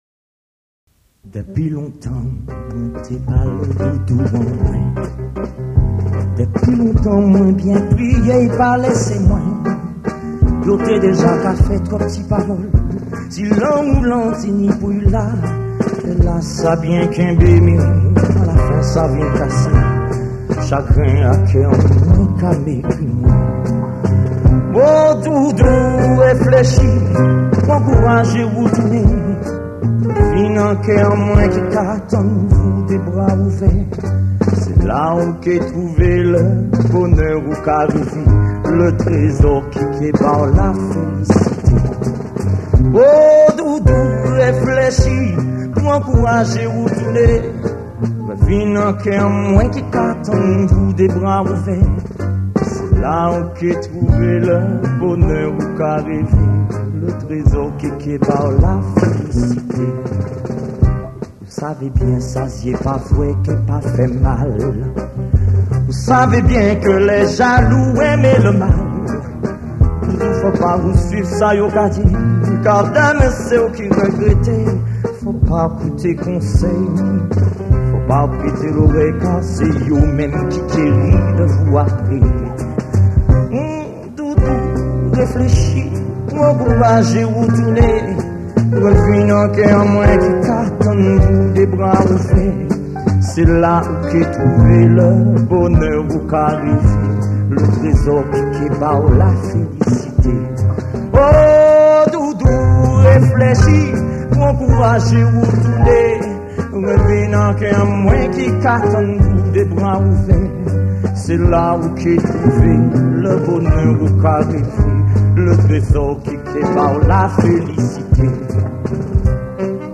accompagnement "Orchestre Universal"